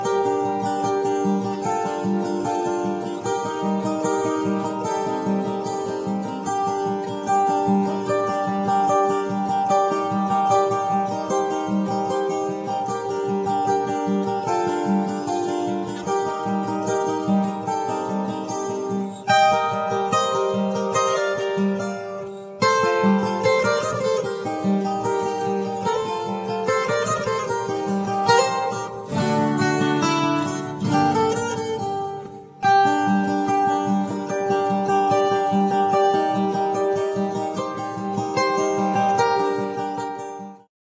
acoustic and flamenco guitar
vocal
acoustic guitar
cajón, perkusja / percussion
Home recording studio 2009